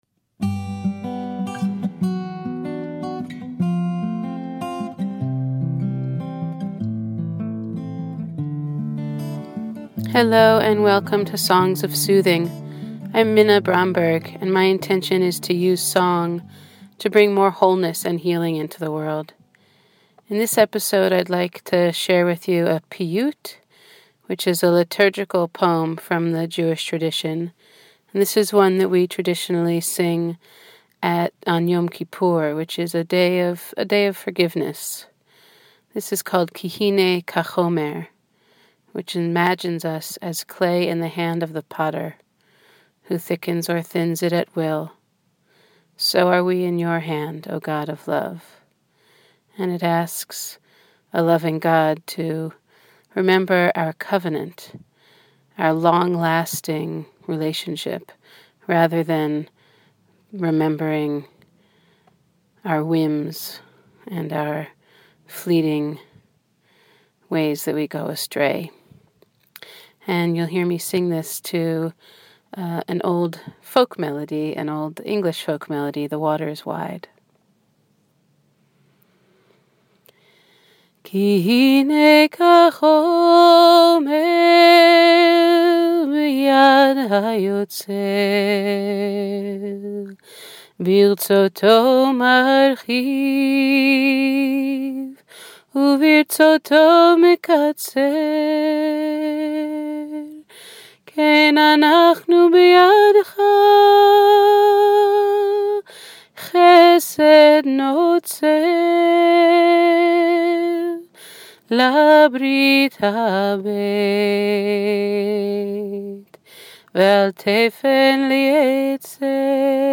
This piyyut (liturgical poem) is a favorite of mine. In it we imagine ourselves as clay in the hands of a potter, as glass worked by a glassblower, as the tiller in the hand of the helmsman.